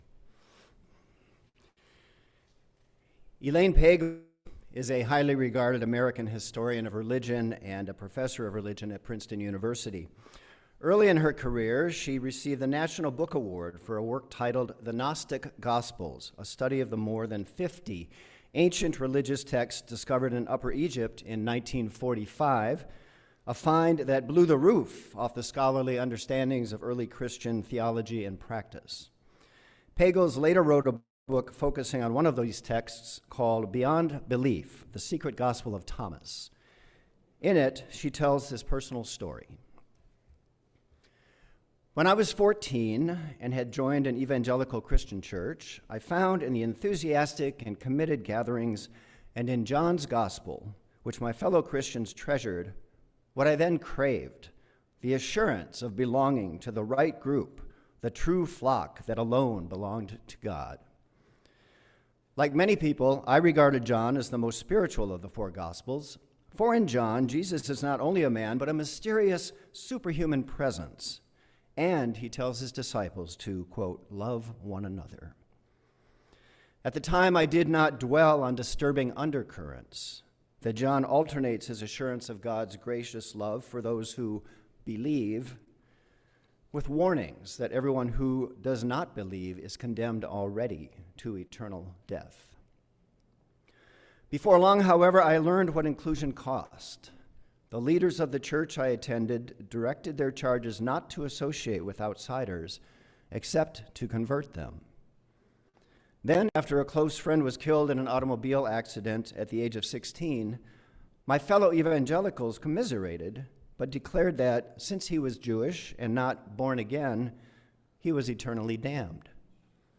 Sermon audio can be found on each service's page (select the service title below), followed by a video of the full service if available (starting April 12, 2020